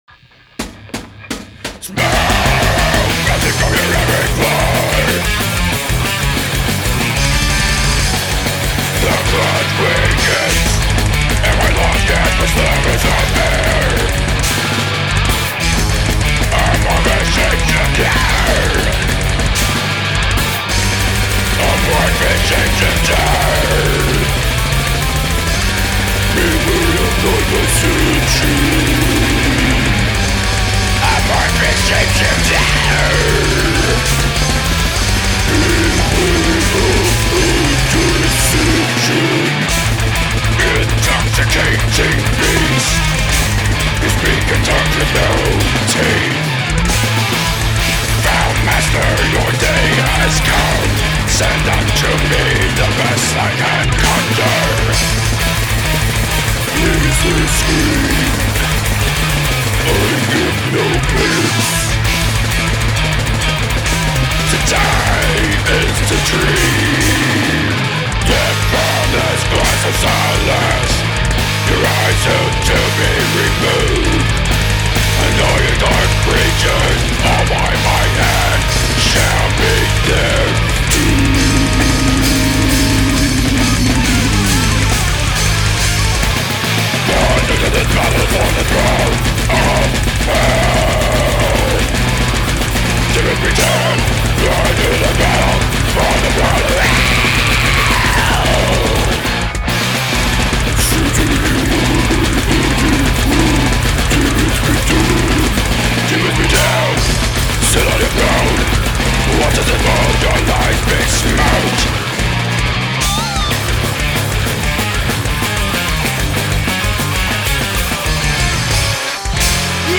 bass and vocals